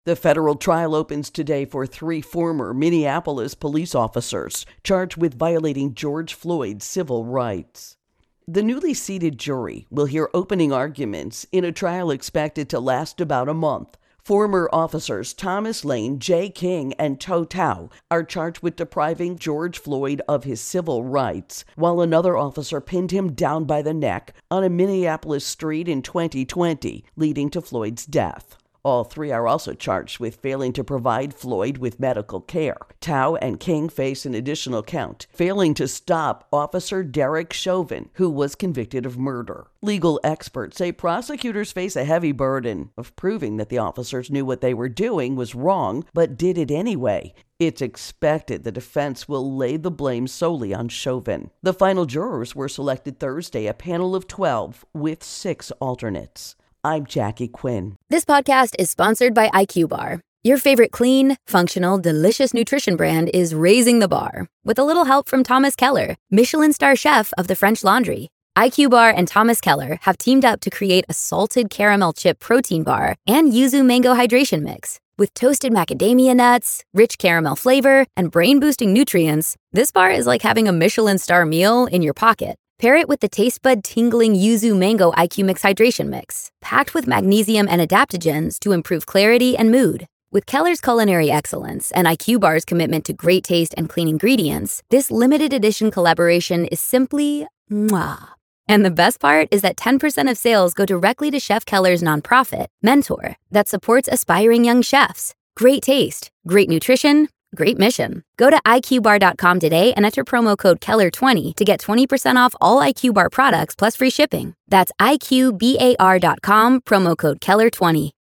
George Floyd Officers Civil Rights Intro and Voicer